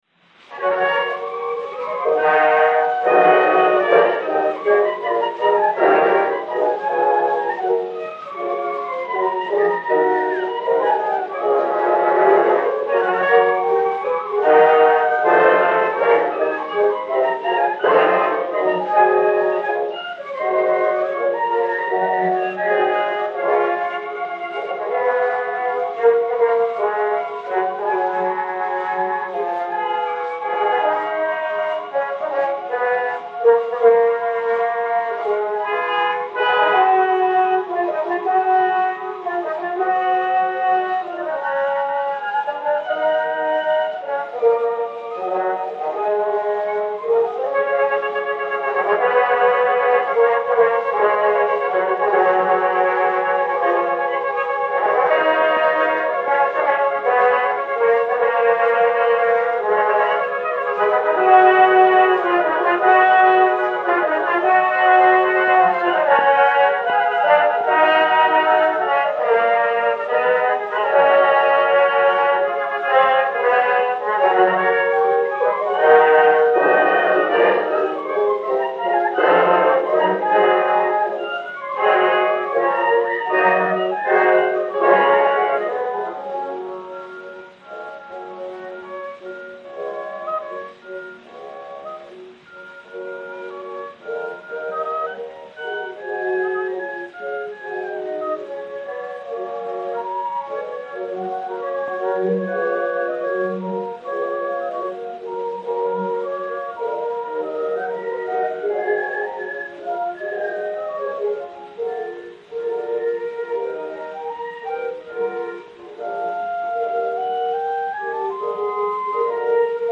Orchestre des Concerts Colonne dir. Edouard Colonne
Pathé saphir 90 tours 8937, réédité sur 80 tours 6208, enr. à Paris en 1906/1907